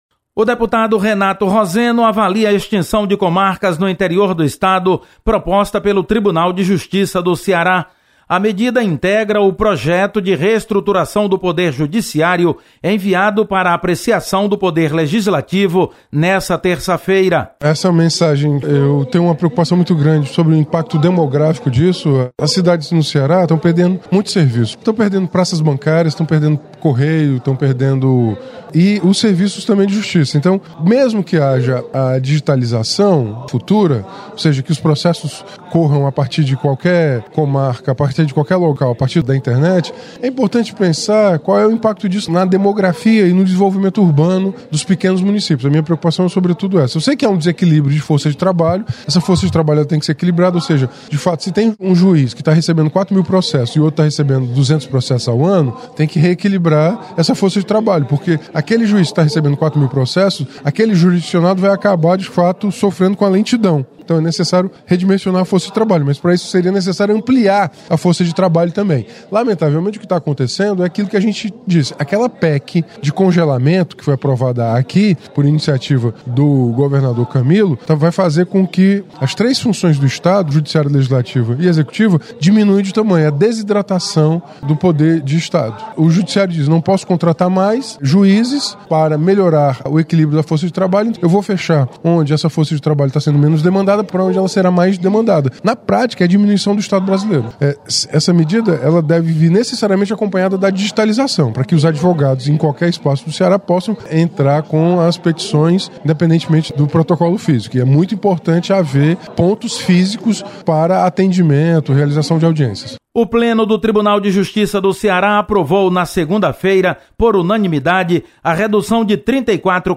Deputado Renato Roseno avalia impacto da extinção de comarcas no interior.